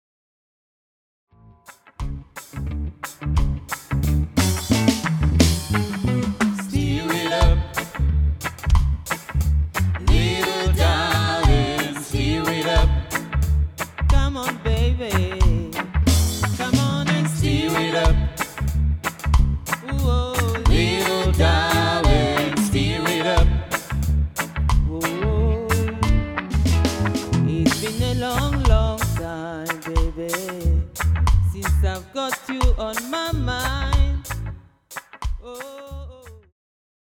Demosounds
Cover
Gesang
Gitarre
Bass
Schlagzeug